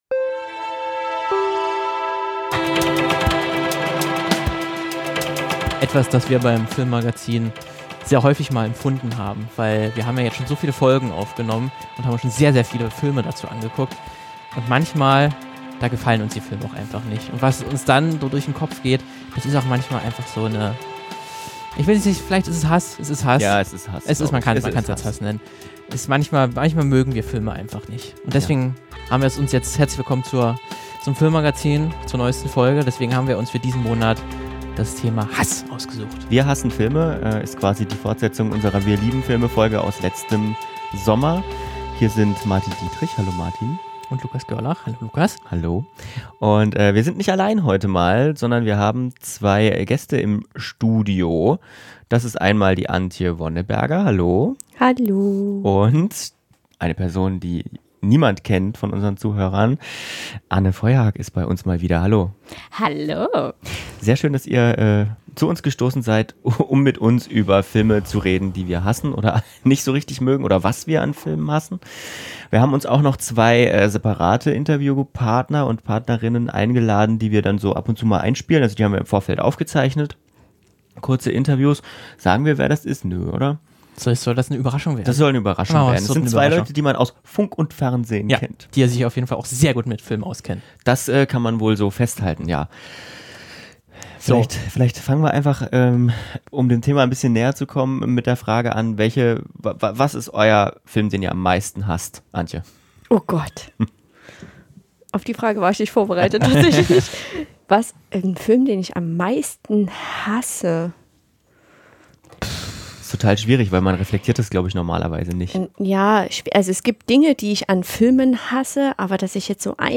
In unserer Sommer-Folge haben wir uns mit Filmkenner*innen zusammengesetzt und sie gefragt, welche Filme sie so richtig hassen.